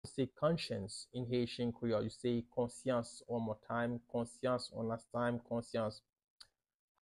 How to say “Conscience” in Haitian Creole – “Konsyans” pronunciation by a native Haitian teacher
“Konsyans” Pronunciation in Haitian Creole by a native Haitian can be heard in the audio here or in the video below:
How-to-say-Conscience-in-Haitian-Creole-–-Konsyans-pronunciation-by-a-native-Haitian-teacher.mp3